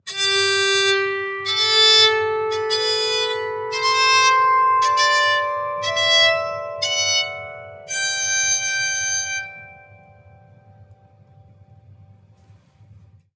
Soprano Psaltery scale sequence - G - 114.mp3
Original creative-commons licensed sounds for DJ's and music producers, recorded with high quality studio microphones.
soprano_psaltery_scale_sequence_-_g_-_114_bqj.ogg